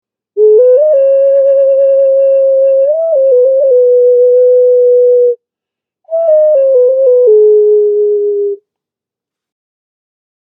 Primitive Bear Ocarina Flute
Animal Bear ocarina, sound healing instruments, black clay pottery.
This Instrument produces a lovely melody.
A recording of the sound of this particular ocarina is in the top description, just click on the play icon to hear the sound.
This musical instrument  is 8 inches x 5 inches across and 2 inches tall.